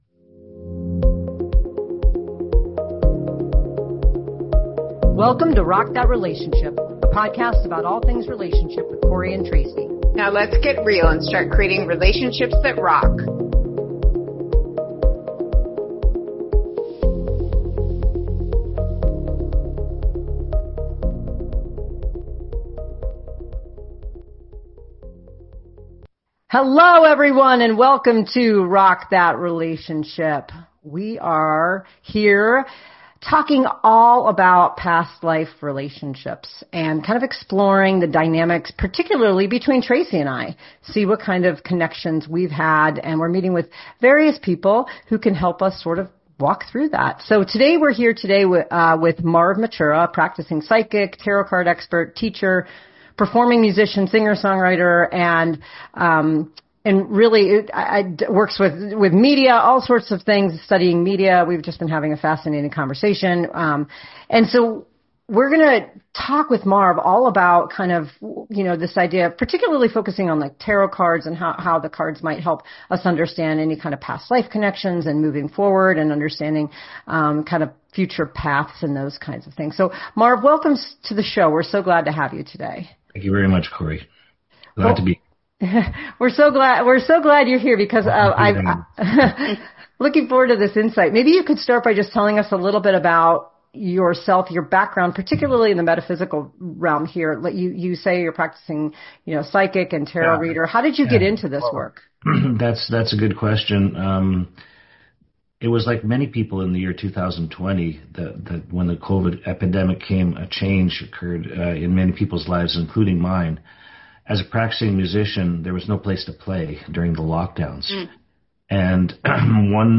Tune in for this incredibly thought-provoking discussion with psychic tarot reader